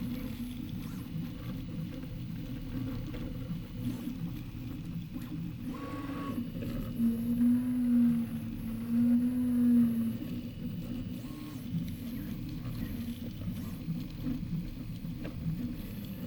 PrintingFilament2.wav